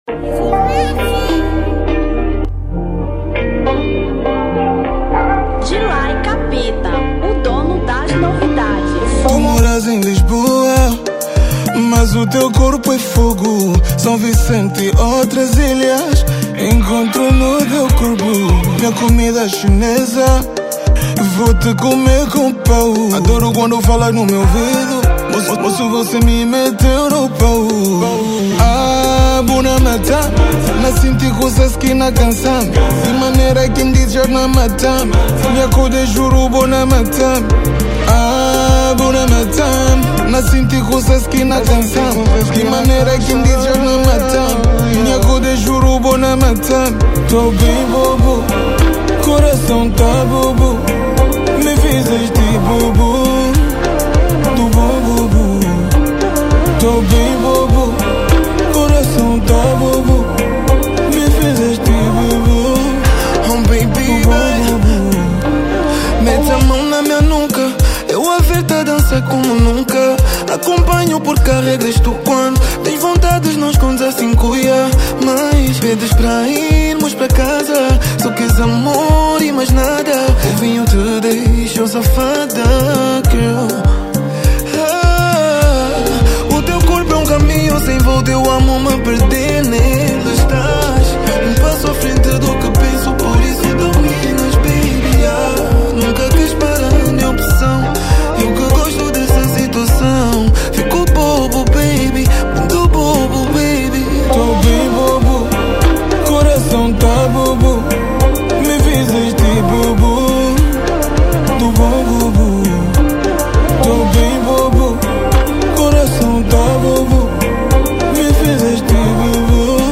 Afro Pop 2024